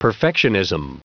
Prononciation du mot perfectionism en anglais (fichier audio)